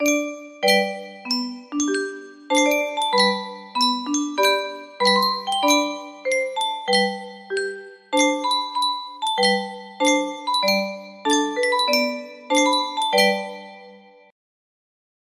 Clone of Yunsheng Spieluhr - Aida Triumphmarsch Y943 music box melody